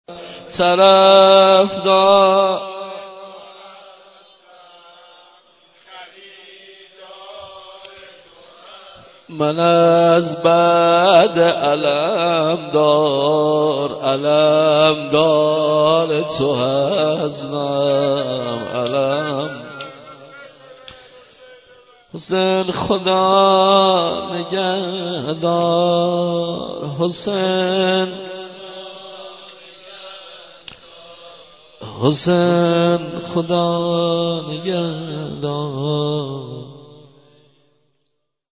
زینب کبری - - -- - -نوحه گودال قتلگاه- - -